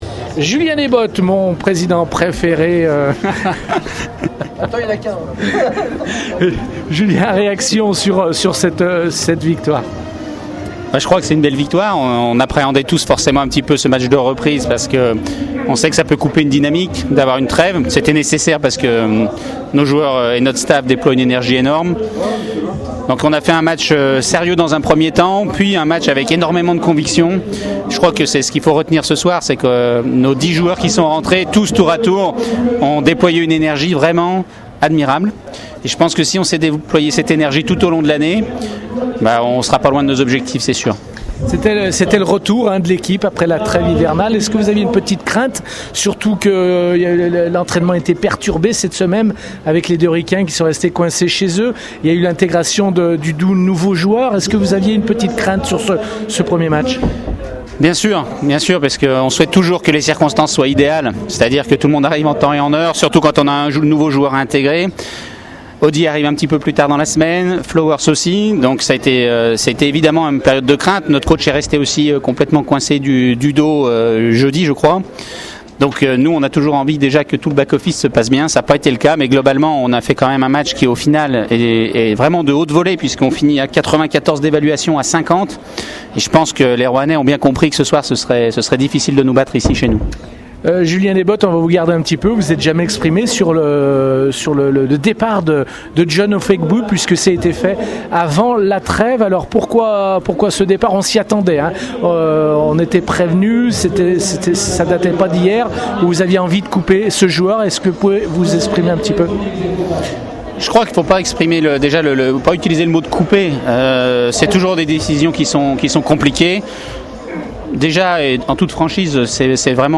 Retrouvez les réactions d’après-match au micro Radio Scoop